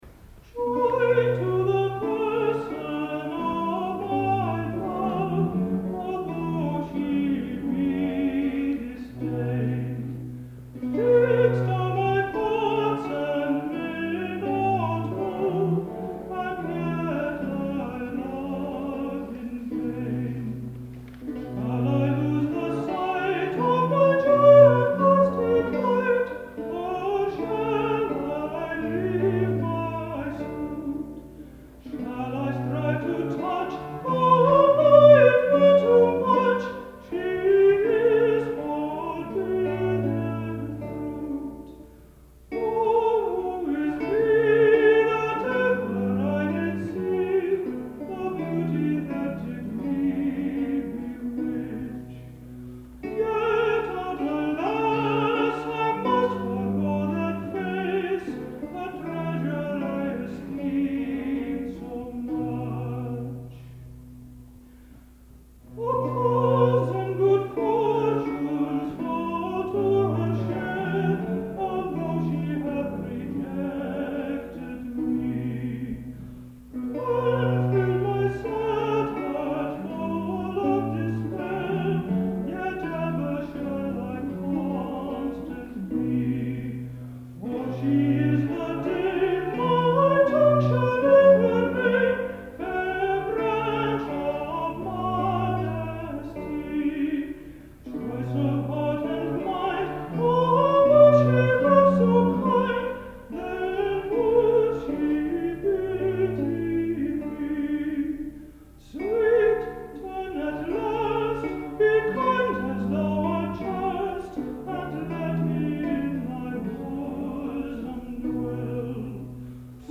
countertenor
Lute
viol